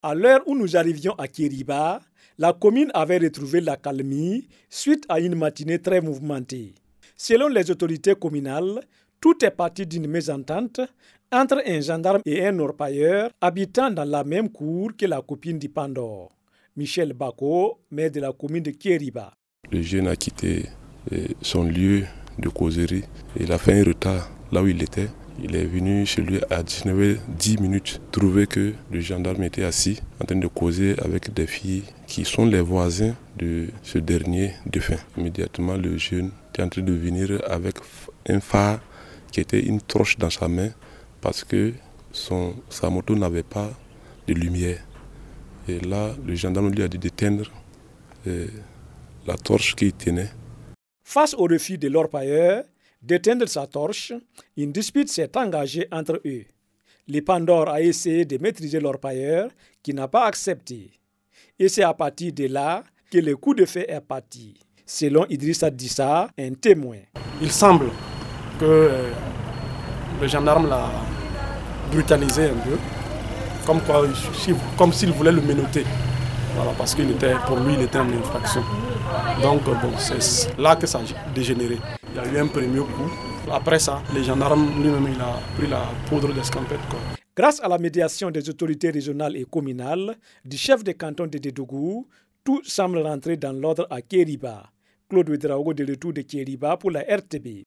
Drame à Tchériba: des témoins dont le maire reviennent sur les faits!
REPORTAGE-INCIDENT-MEURTRIER-0àTCHERIBA.mp3